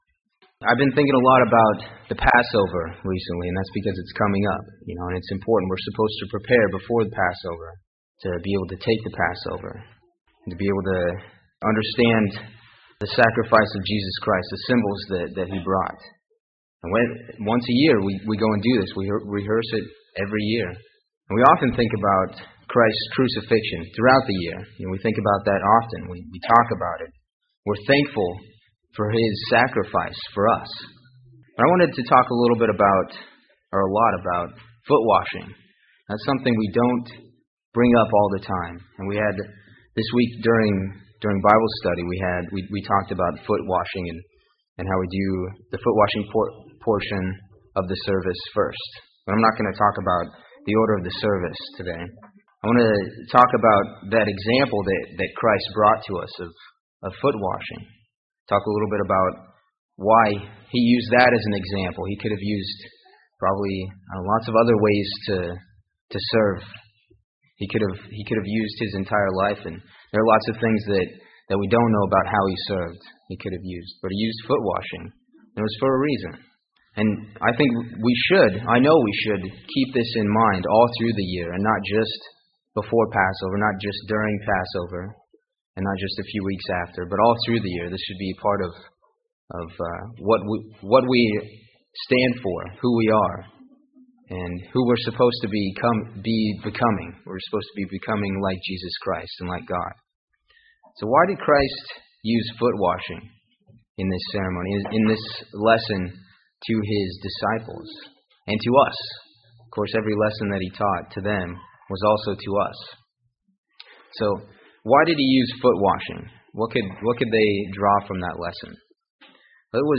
How often do we think about Jesus Christ's example of foot washing from the Passover? This sermon explores the reason that such an important demonstration of service and love was added to the Passover service, as well as why we should recall Christ's example more than once a year.
Given in Huntsville, AL